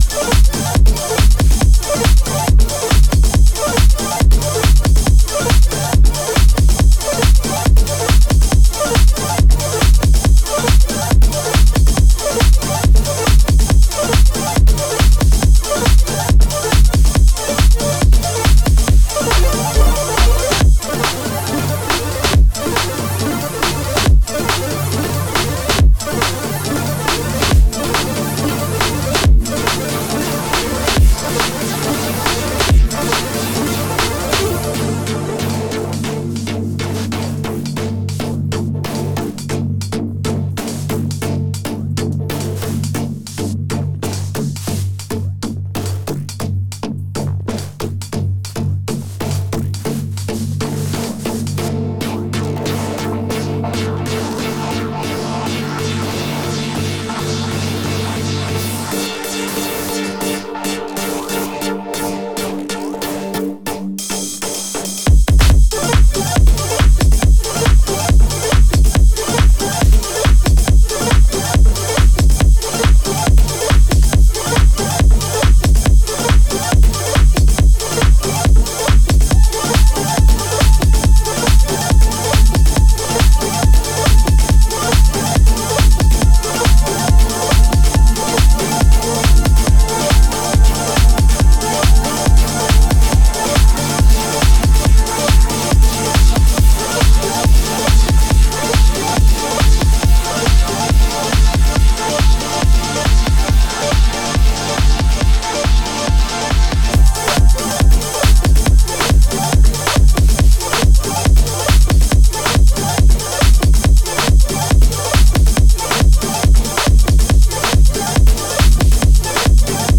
歪められたリードがインパクト大なファンキーテクノ
キャッチーなフックも満載のポスト・ベース最前線です。